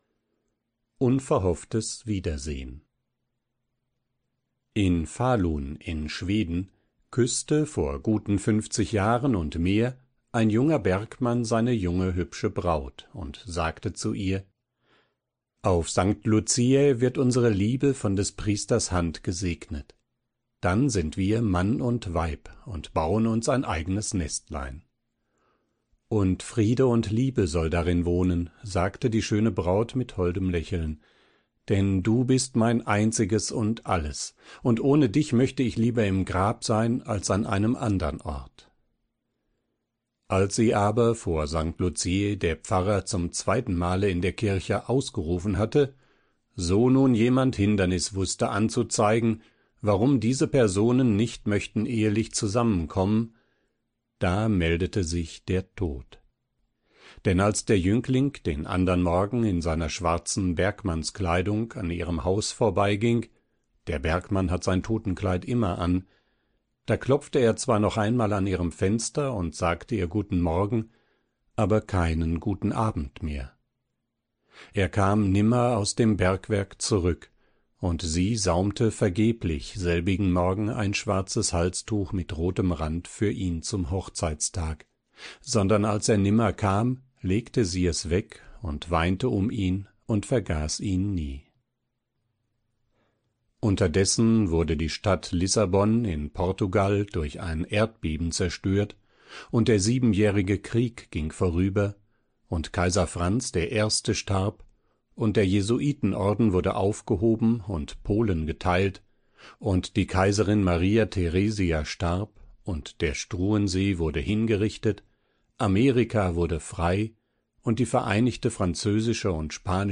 Librivox-Recording/Aufnahme - Licencefree/Lizenzfrei / in public ownership/in öffentlichem Besitz